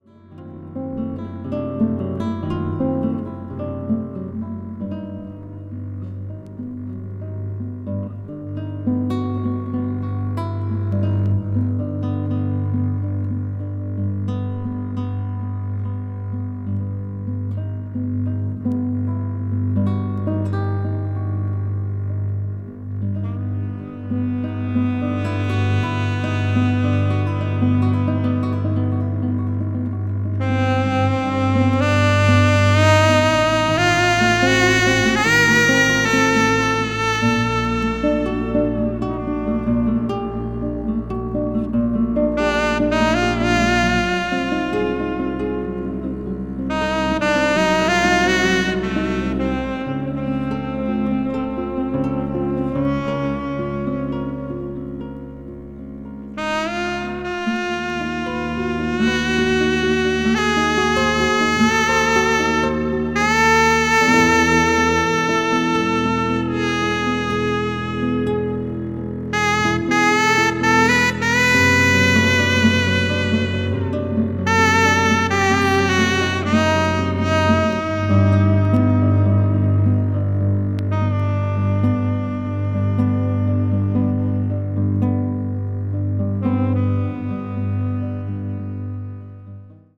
奏でられるすべての音がじんわりと染みてくるとても静かな世界